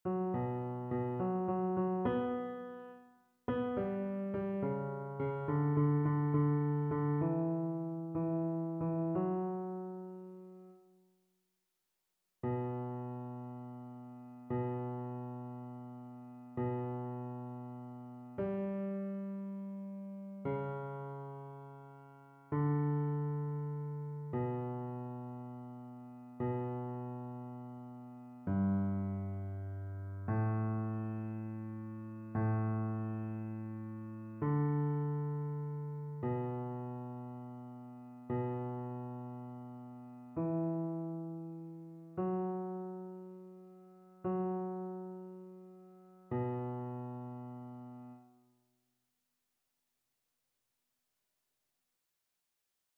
Basse
annee-c-temps-de-l-avent-3e-dimanche-cantique-d-isaie-basse.mp3